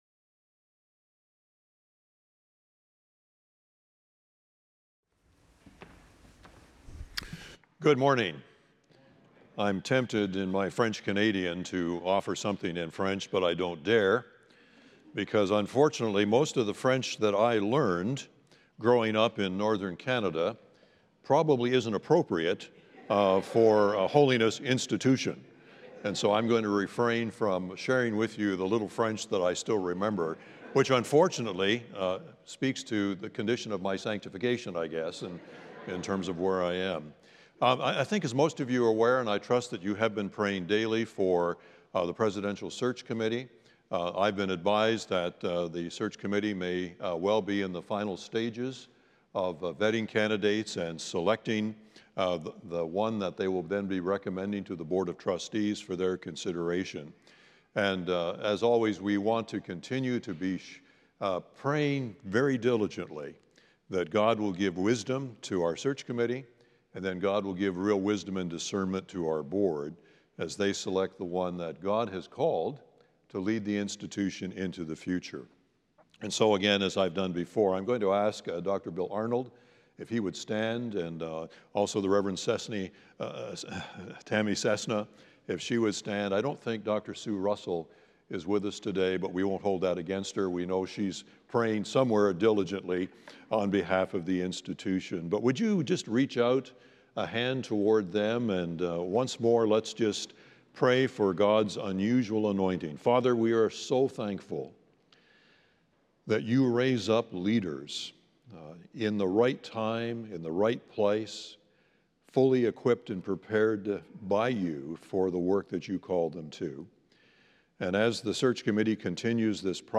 The following service took place on Thursday, February 6, 2025.